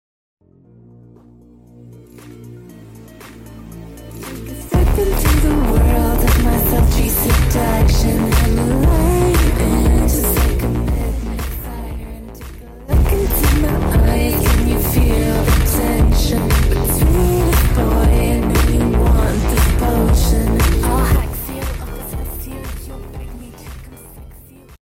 slowed & reverb